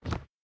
04_书店内_扶正书本.ogg